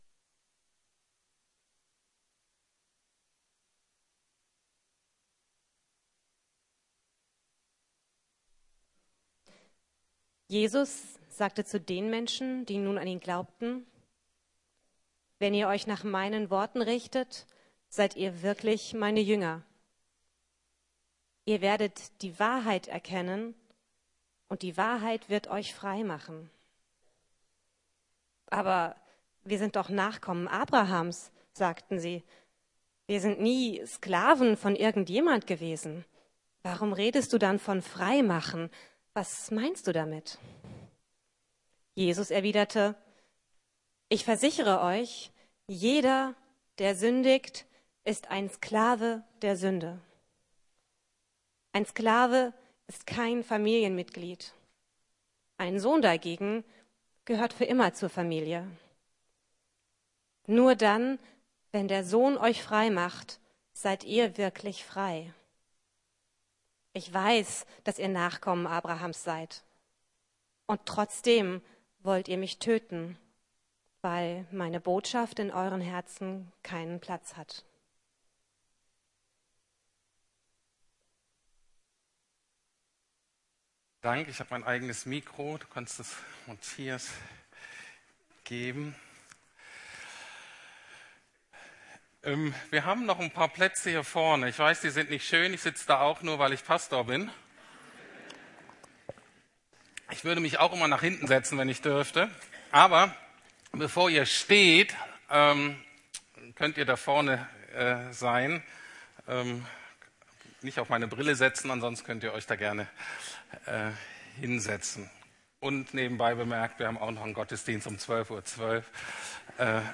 Lieben, was Jesus liebt: Die Freiheit ~ Predigten der LUKAS GEMEINDE Podcast